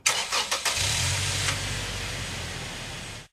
加入冰车僵尸生成音效